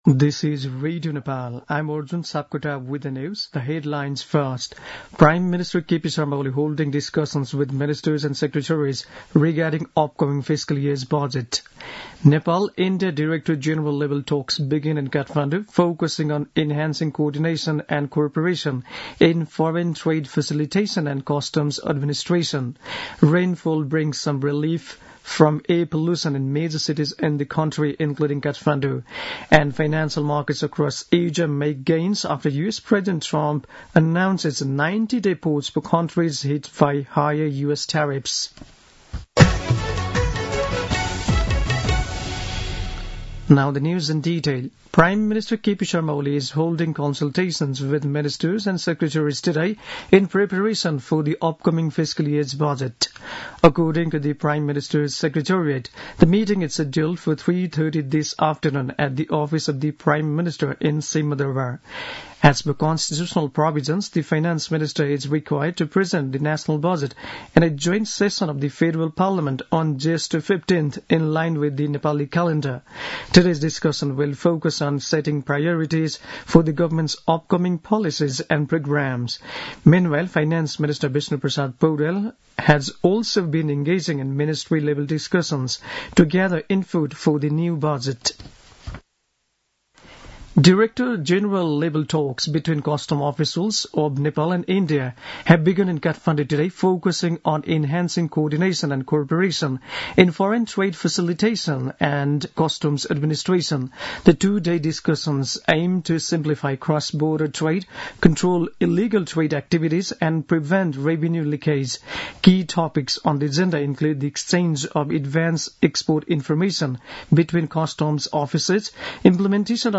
दिउँसो २ बजेको अङ्ग्रेजी समाचार : २८ चैत , २०८१